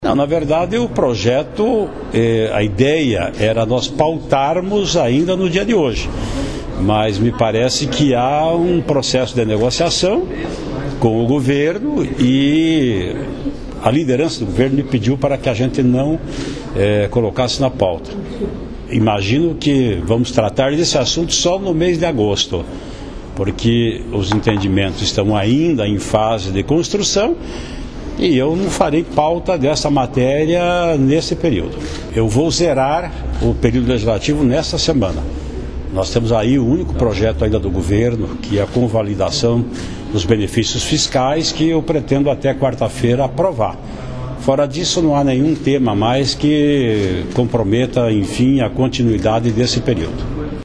Sonora presidente da Alep, deputado Ademar Traiano (PSDB).